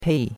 pei4.mp3